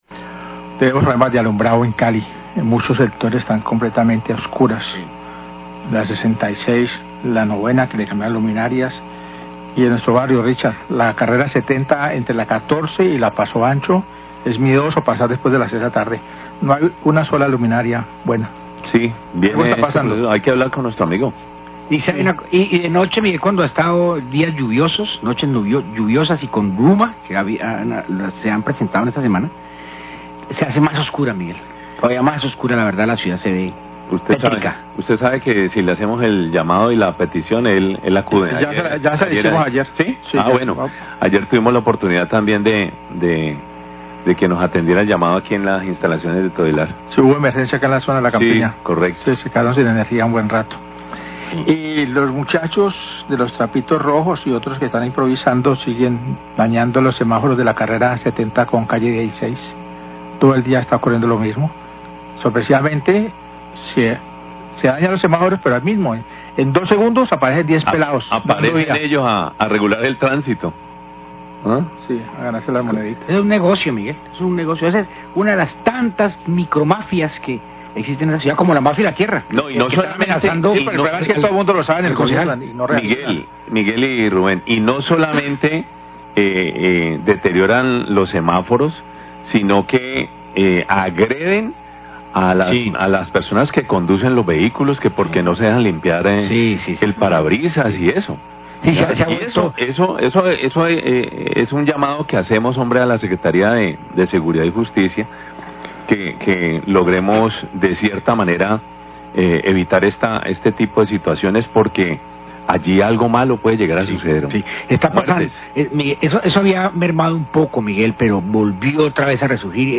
Radio
La mesa de trabajo opina sobre la problemática con algunos semáforos en la ciudad que están ciudad vandalizados por jóvenes que luego intentan regular el tráfico y pedir dinero a los conductores.
Alcalde de Cali hace una breve intervención sobre el refuerzo de unidades de la fuerza pública que llegan a la ciudad para la COP16 y sobre la compra de vehículos para la Policía.